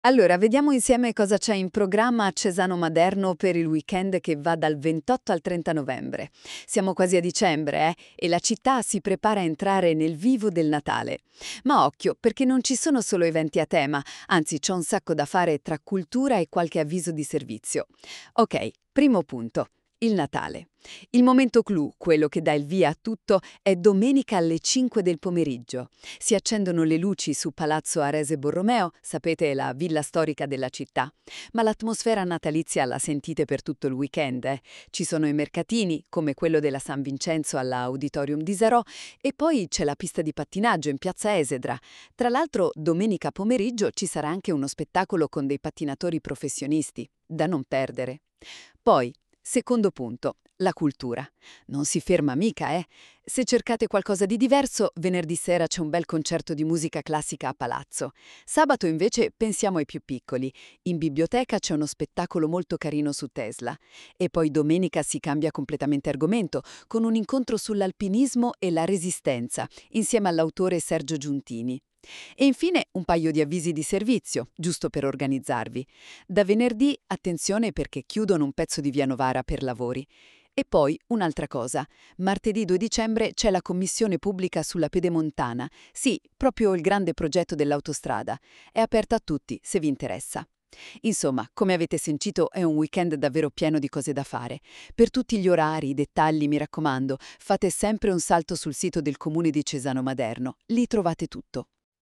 Il podcast è stato realizzato con l’ausilio dell’IA, potrebbe contenere parziali errori nelle pronunce o in alcune definizioni.